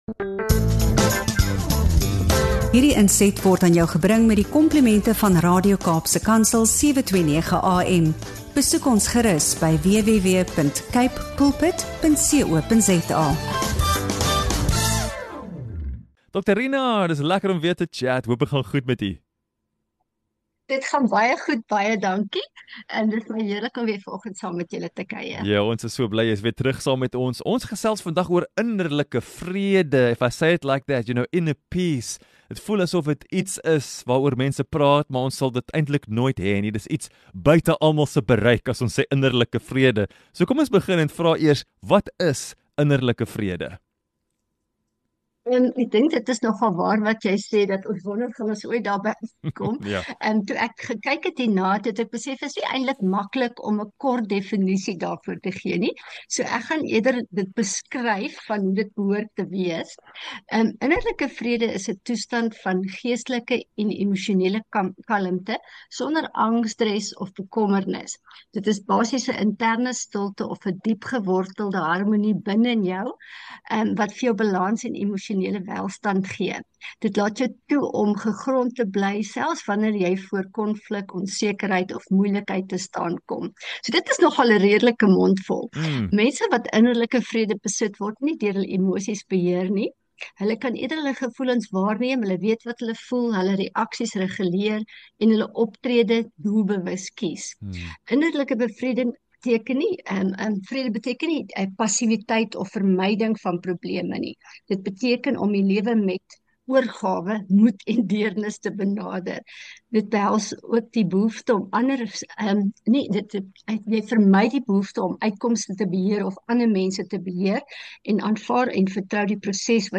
In hierdie diepgaande gesprek